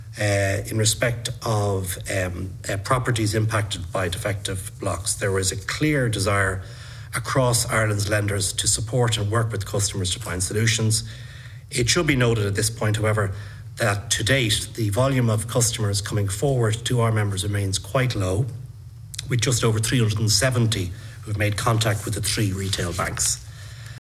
An Oireachtas committee to discuss issues related to Defective Concrete Blocks has gotten underway.
The opening remarks were made by CEO, Banking & Payments Federation of Ireland, Brian Hayes.